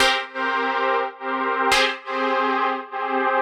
Index of /musicradar/sidechained-samples/140bpm
GnS_Pad-MiscA1:2_140-C.wav